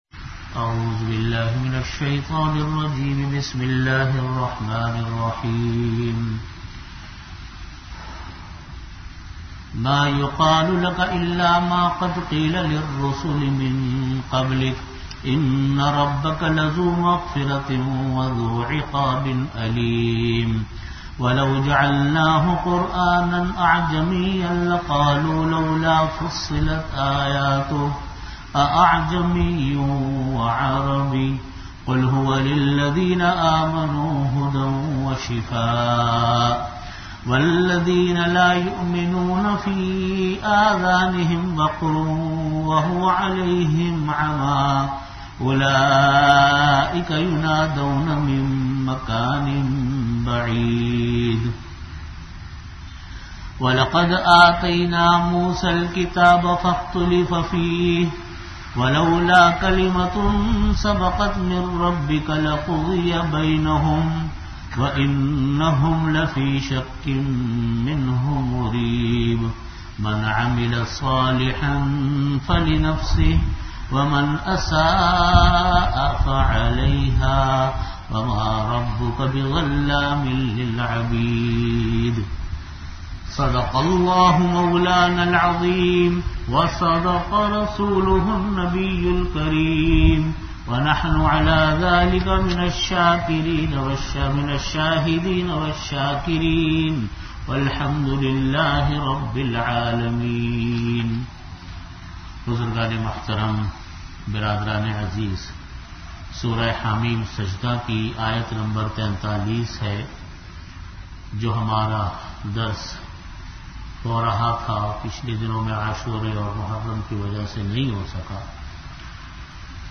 An Islamic audio bayan
Delivered at Jamia Masjid Bait-ul-Mukkaram, Karachi.